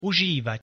pronunciation_sk_uzivat.mp3